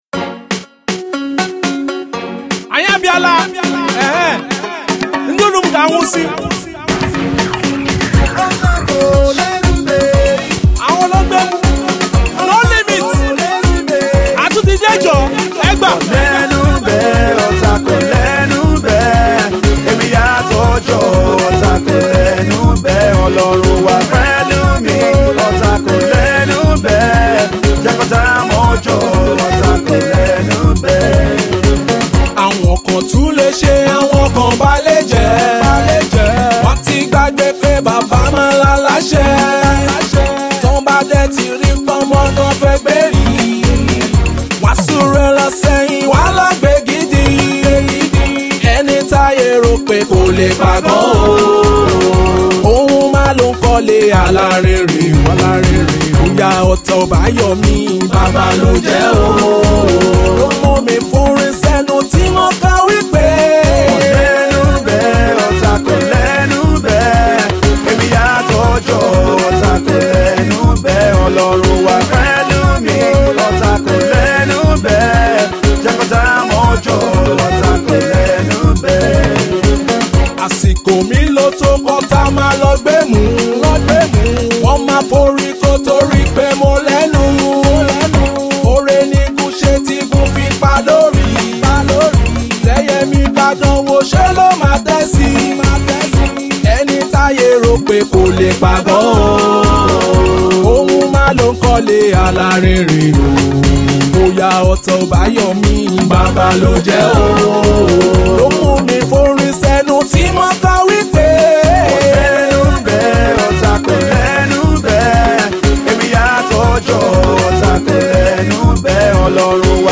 R&B/ Hip-Hop